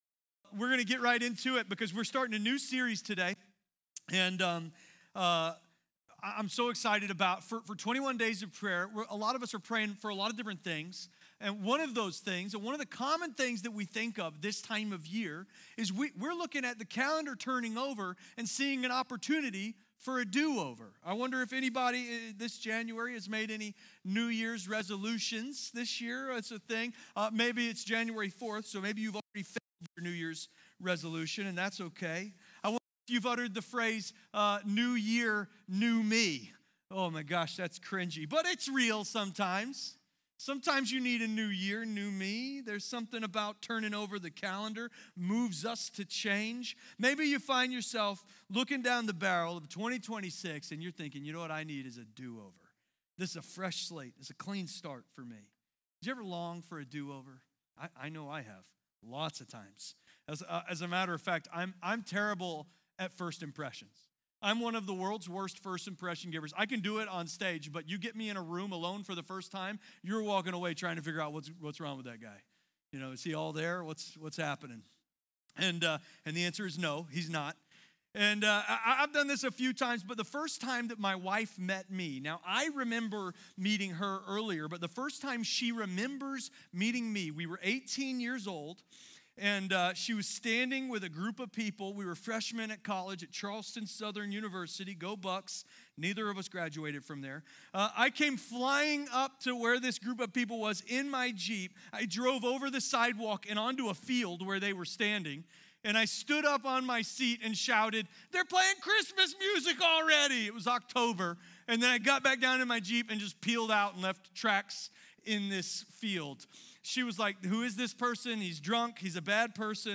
Do Over is a sermon series about God’s grace that meets us in our mistakes and offers a fresh start. No matter what’s behind you, God isn’t finished with your story.